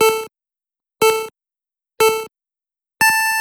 RaceCountdown.wav